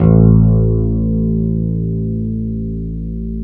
Index of /90_sSampleCDs/Roland L-CD701/BS _E.Bass 5/BS _Dark Basses